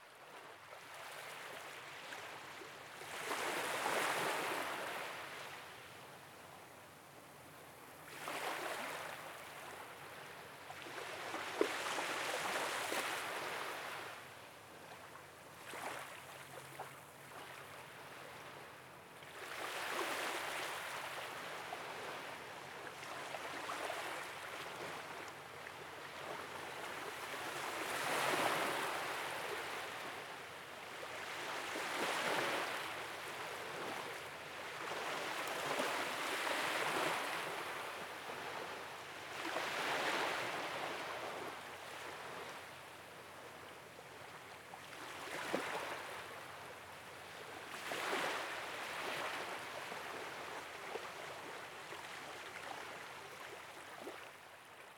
SC Beach Loop 1_0.ogg